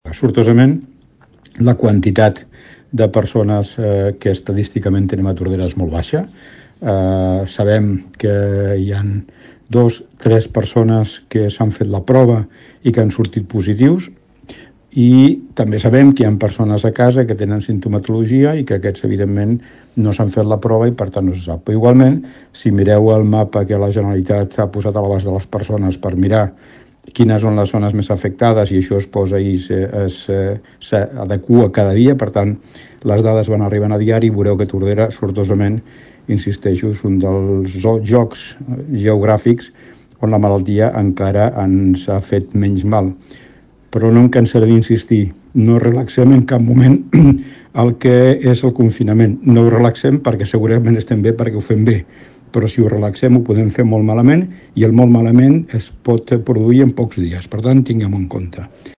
L’Alcalde de Tordera, Joan Carles Garcia recorda que el nostre municipi és de les zones sanitàries del Maresme amb menys casos.